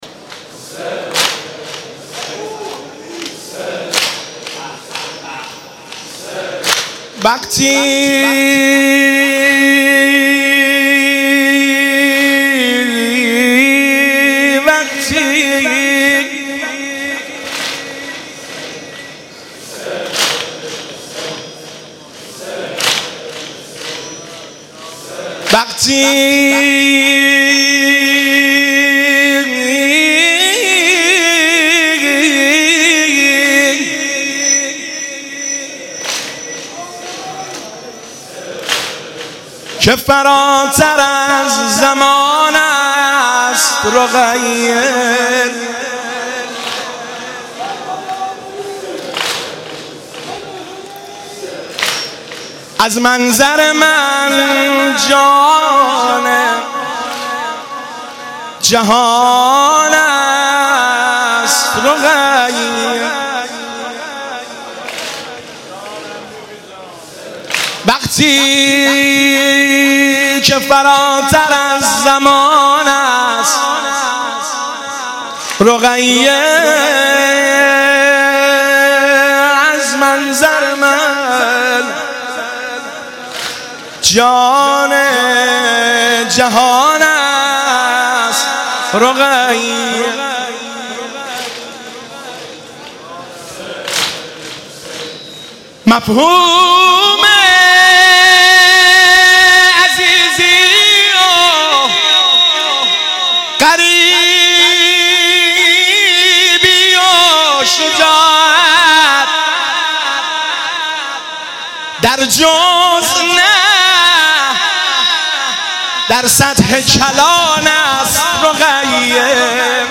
شب سوم محرم - به نام نامیِ حضرت رقیه(س)